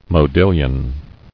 [mo·dil·lion]